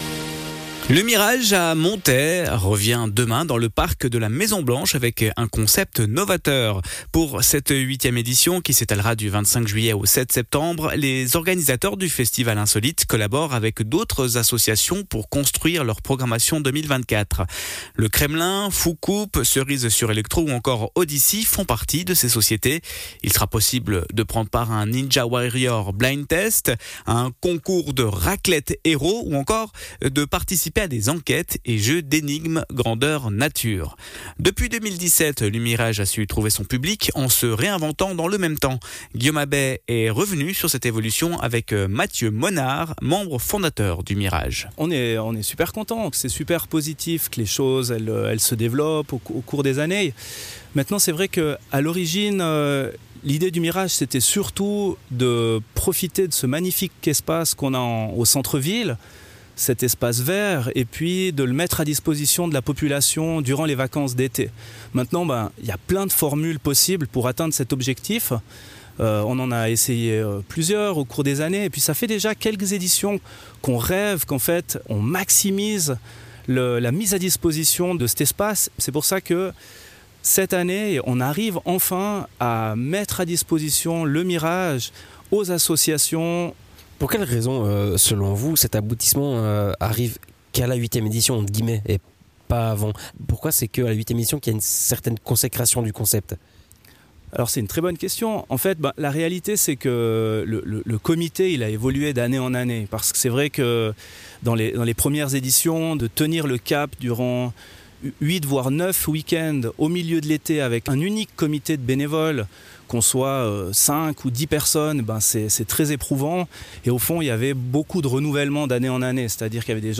Membre fondateur